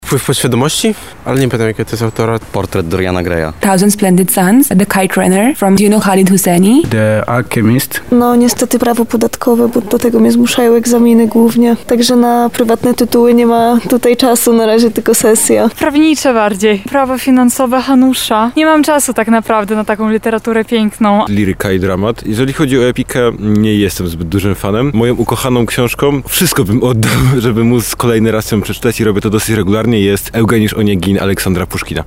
Zapytaliśmy odwiedzających Bibliotekę Główną UMCS o to, jakie tytuły polecają:
sonda